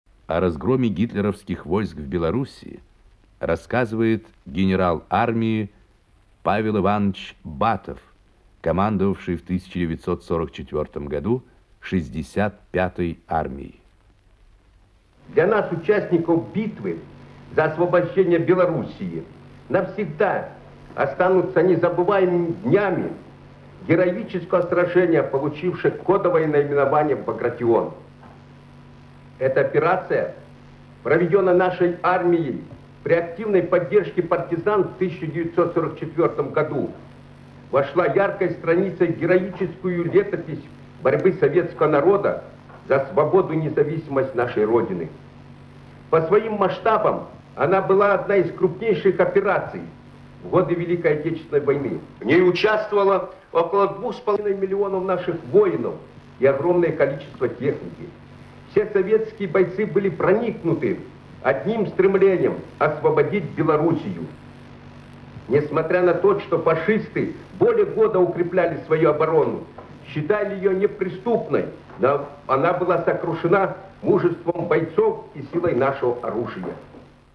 Генерал Армии, дважды Герой Советского Союза  Павел батов рассказывает о разгроме группировки немецко-фашистских войск в Белоруссии (Архивная запись).